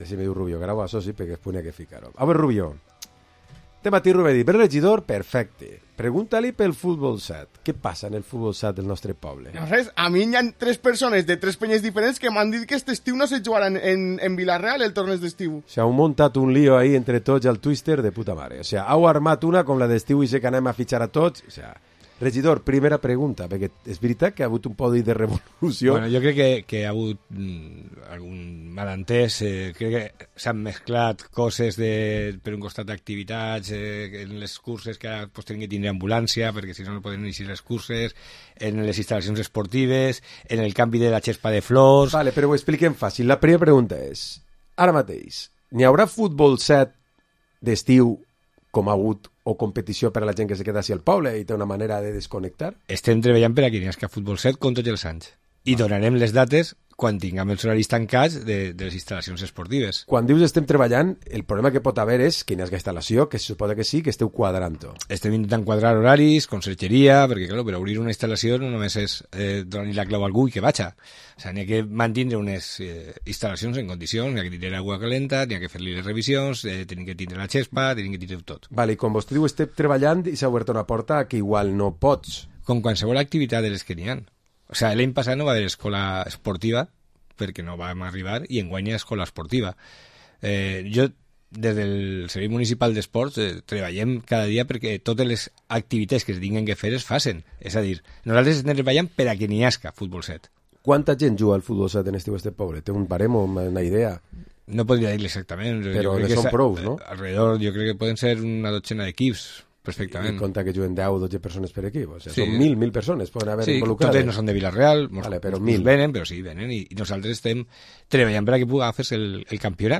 Entrevista Xus Madrigal, regidor d´esports a l´Ajuntament de Vila-real